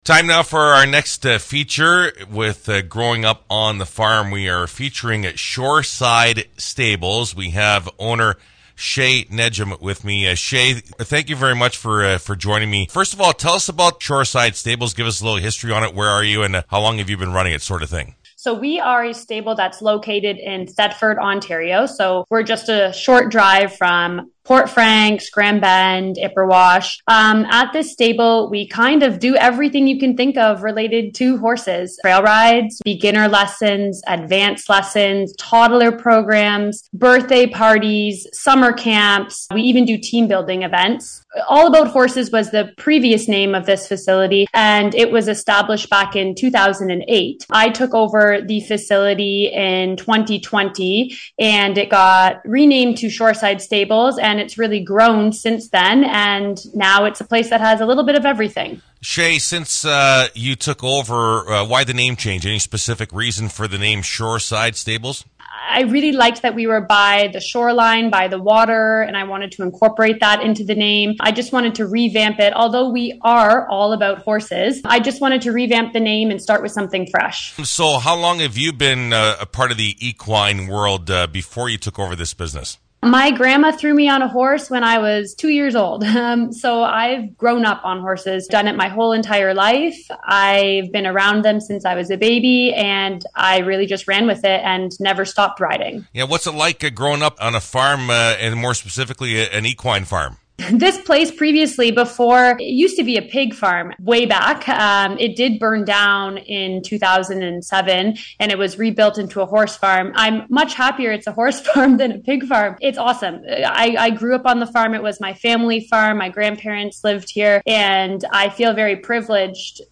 Here is the complete interview